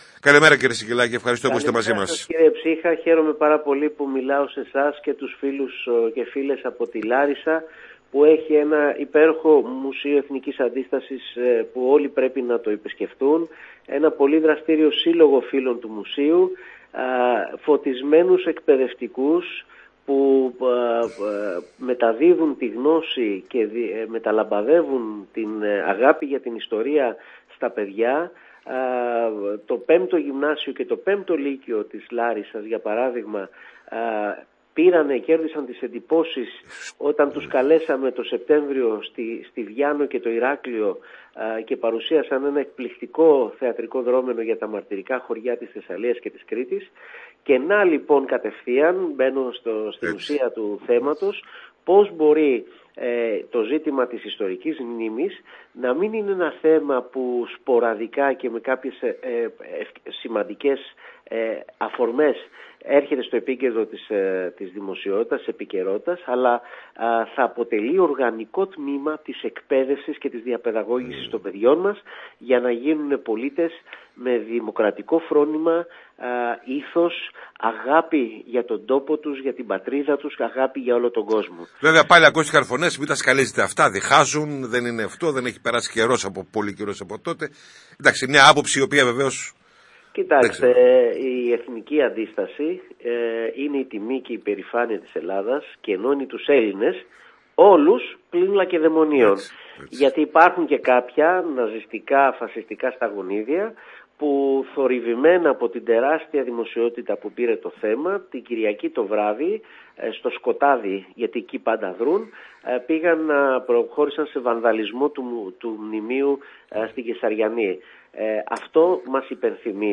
Η εκπομπή “Θεσσαλικο Περισκόπιο” μεταδίδεται από τους ραδιοφωνικούς σταθμούς της ΕΡΤ σε Λάρισα και Βόλο.